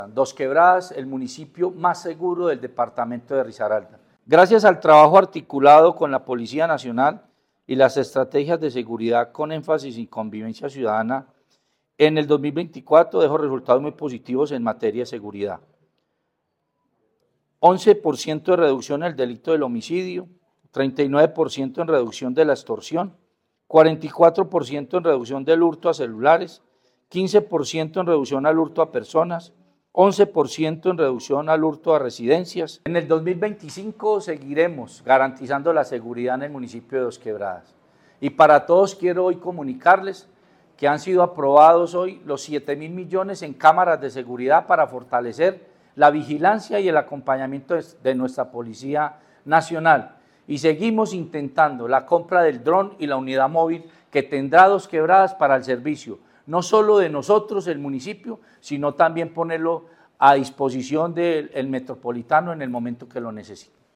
ALCALDE-CONSEJO-DE-GOBIERNO-.mp3